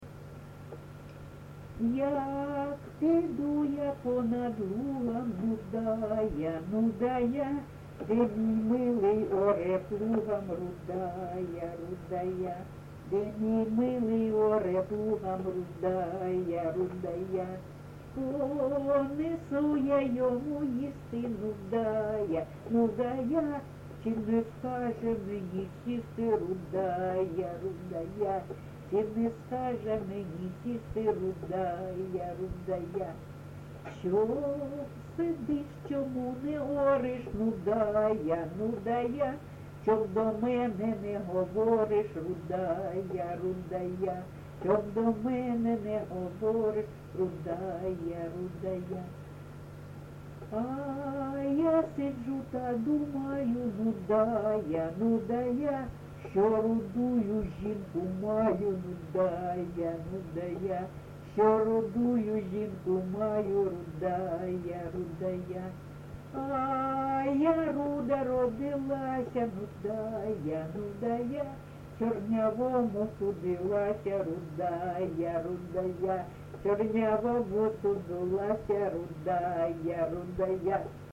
ЖанрЖартівливі
Місце записус. Чорнухине, Алчевський район, Луганська обл., Україна, Слобожанщина